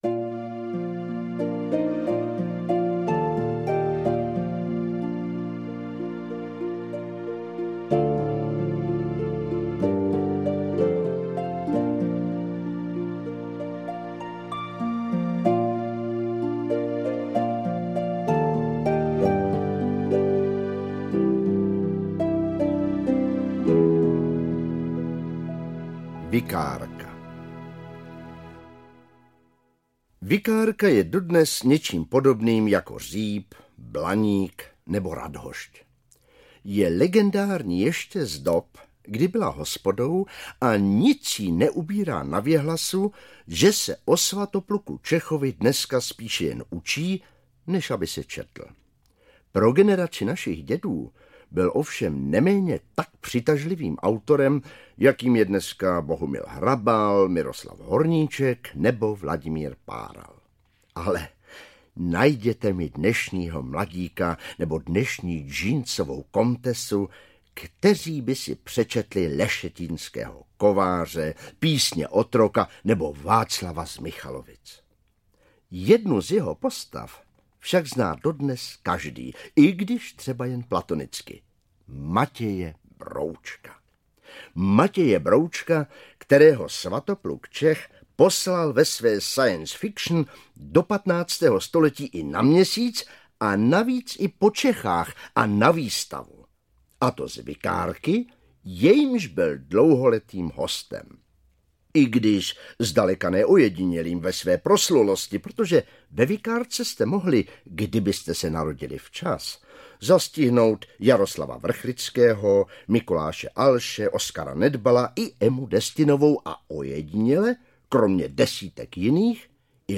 Po Praze chodím… audiokniha
Ukázka z knihy